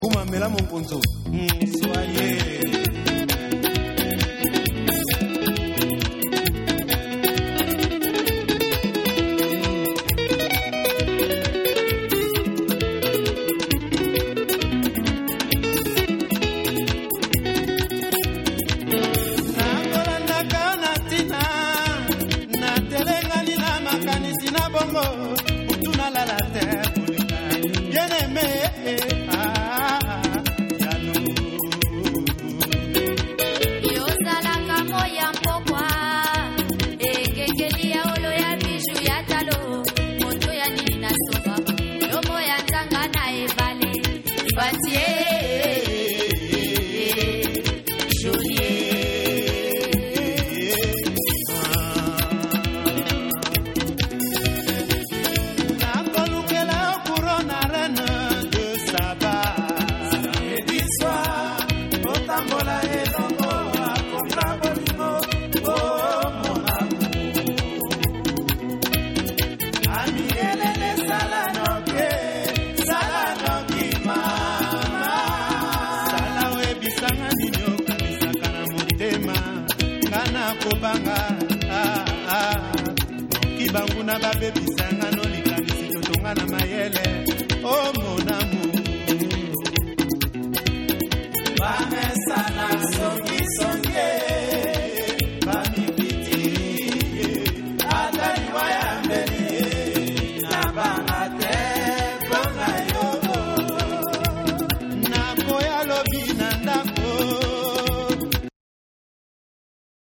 WORLD / CUMBIA